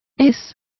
Complete with pronunciation of the translation of sediment.